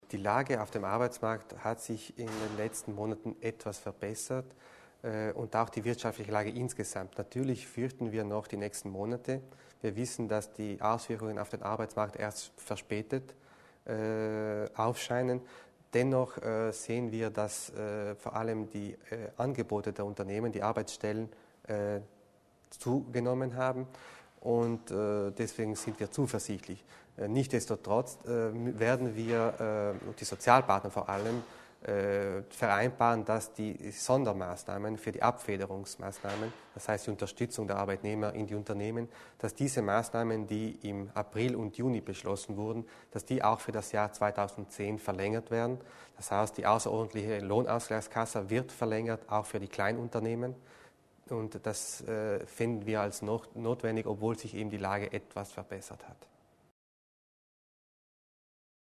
Krisen-Arbeitsgruppe tagt: O-Ton